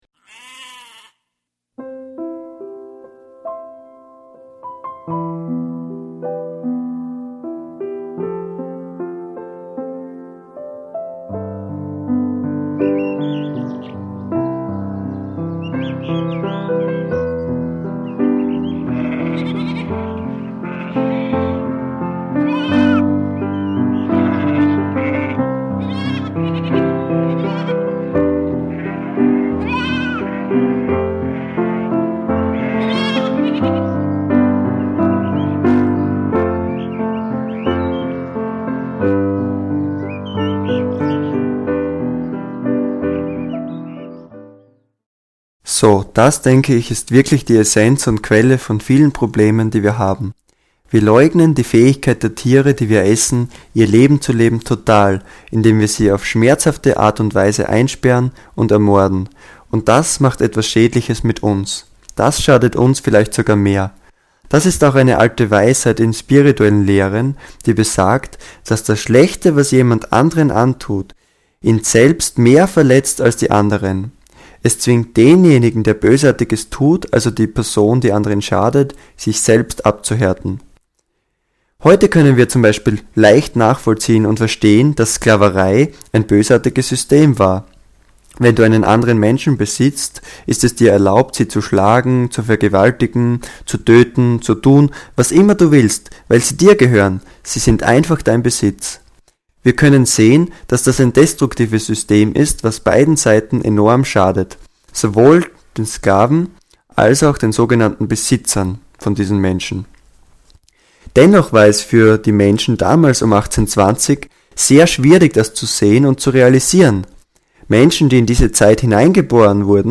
is a discourse, with short musical interludes